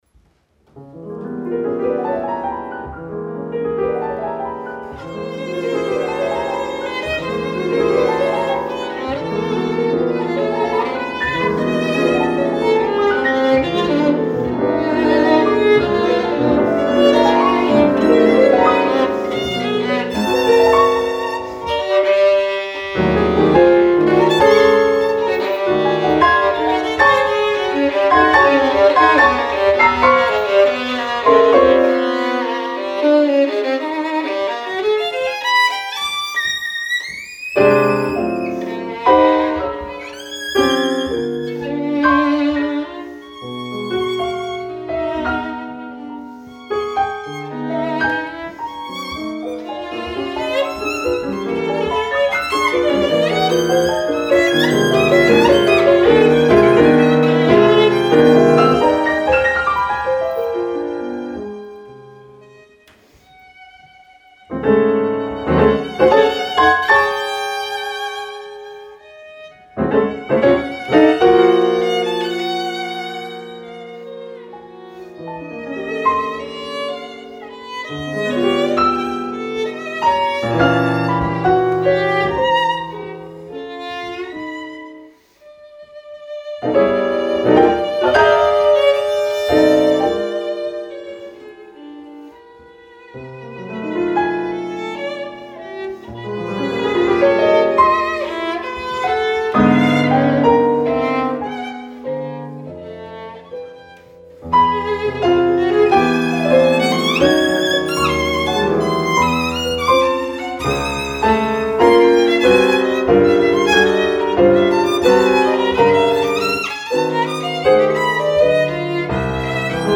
Venue: Bantry Library
Instrumentation: vn, pf Instrumentation Category:Duo
violin
piano